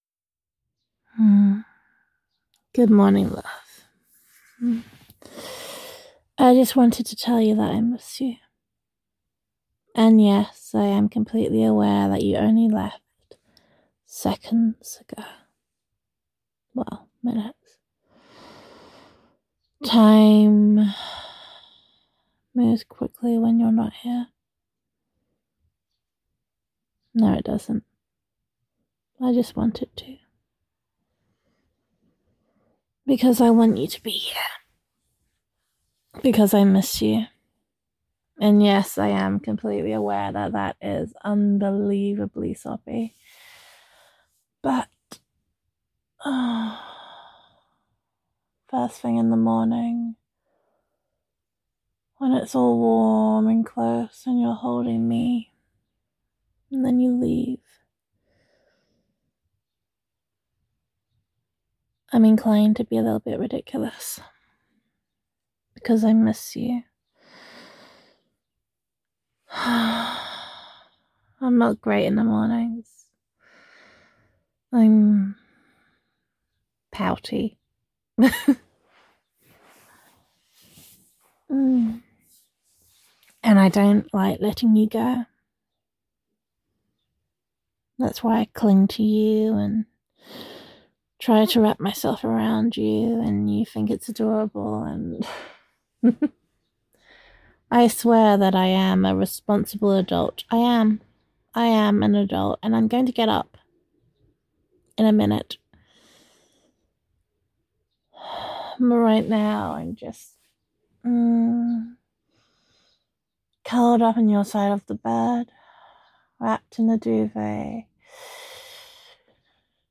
Same voicemail as posted earlier just without the telephone EQ.
[F4A] Good Morning, Love [I Miss You][Even Though You Only Just Left][Unbelievably Soppy][Pouty][Telephone Eq][Gender Neutral][Girlfriend Voicemail]